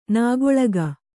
♪ nāgoḷaga